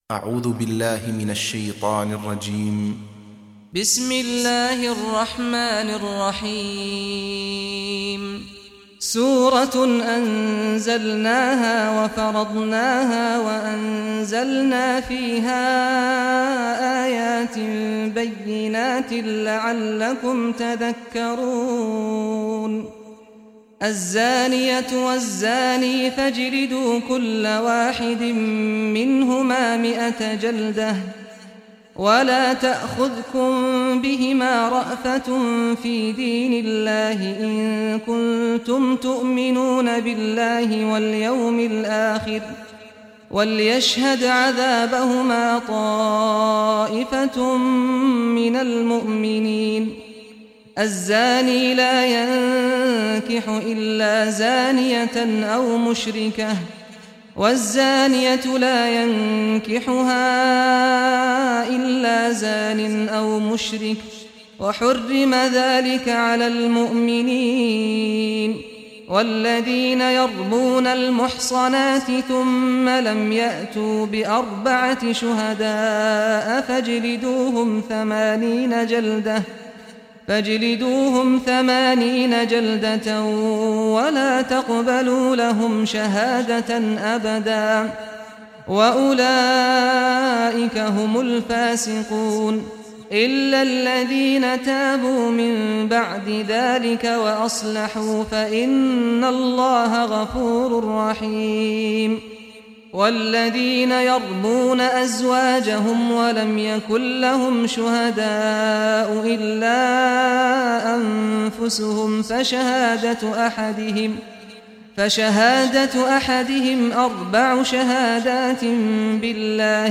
Surah Nur Recitation by Sheikh Saad al Ghamdi
Surah Nur, listen or play online mp3 tilawat / recitation in Arabic in the voice of Sheikh Saad al Ghamdi.